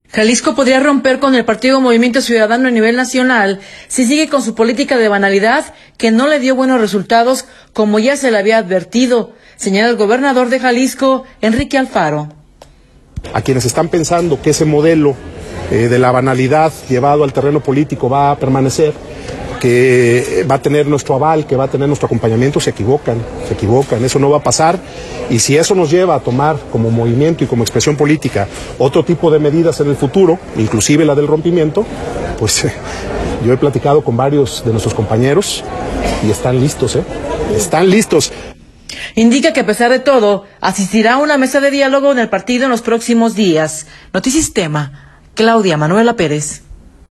audio Jalisco podría romper con el Partido Movimiento Ciudadano a nivel nacional si sigue con su política de banalidad que no le dio buenos resultados, como se le había advertido, señala el gobernador de Jalisco, Enrique Alfaro.